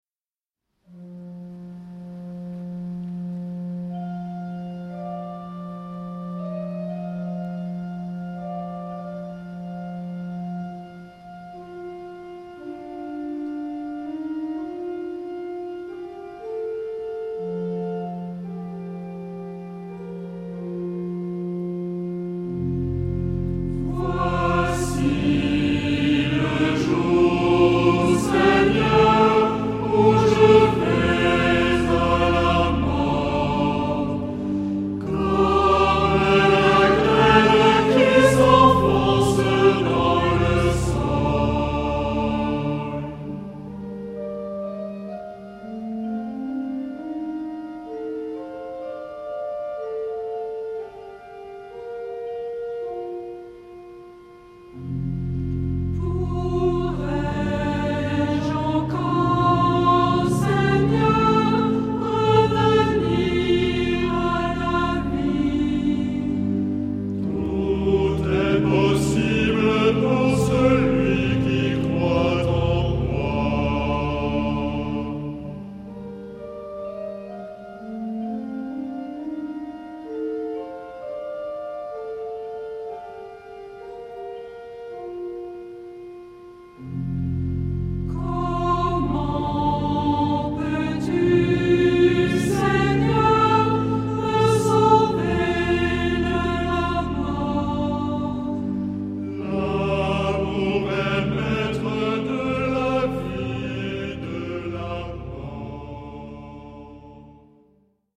Genre-Style-Form: Prayer
Mood of the piece: majestic ; andante ; calm
Type of Choir:  (1 unison voices )
Instrumentation: Organ  (1 instrumental part(s))
Tonality: D minor